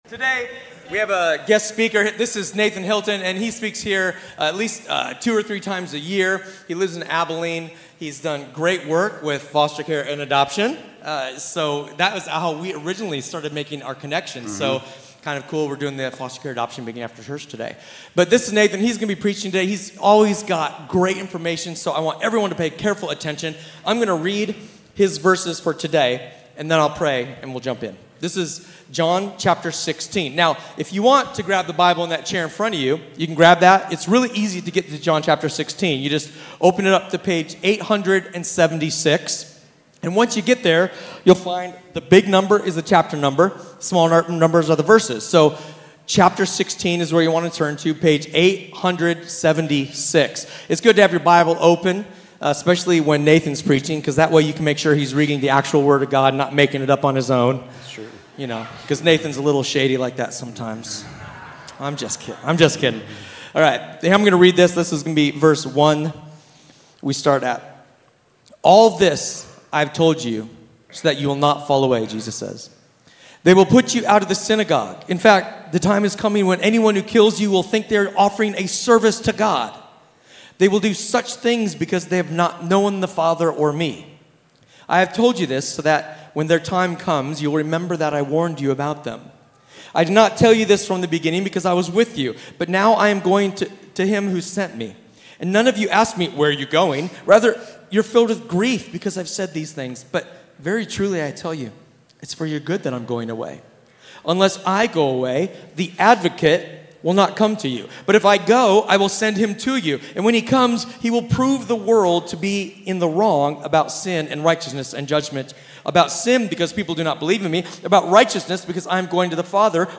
A sermon from the series "Guest."